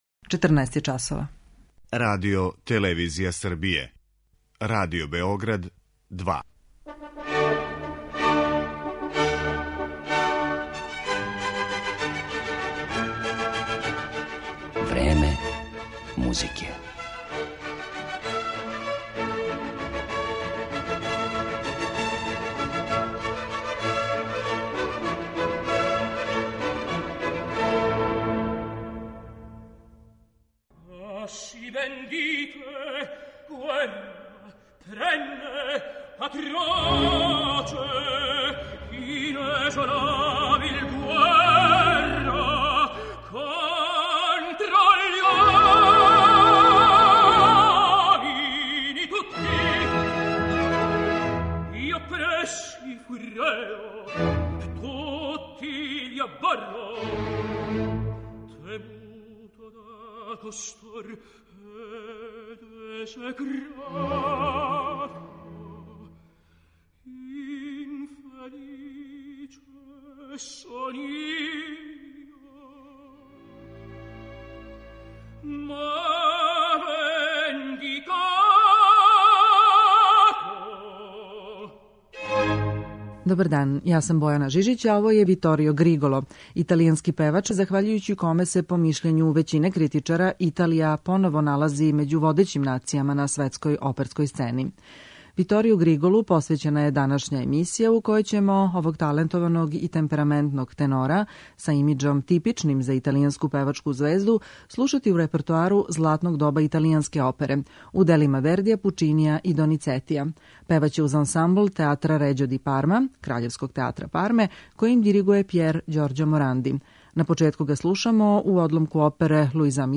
Данашња емисија Време музике посвећена је великом италијанском лирском тенору Виторију Григолу, кога критичари описују као харизматично шармантног, темпераментог и свестрано обдареног.
Он је својим моћним и колористички богатим гласом, виртуозним и изражајним певањем и убедљивом сценском појавом, за кратко време освојио и оперску публику и стручњаке широм света. Данас ћемо слушати како овај Тосканац пева арије из опера Ђузепа Вердија, Ђакома Пучинија и Гаетана Доницетија.